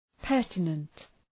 Προφορά
{‘pɜ:rtənənt}